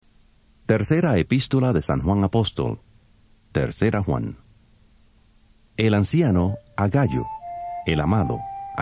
Biblia Reina Valera 1995 Dramatizada > 25 - 3ra de Juan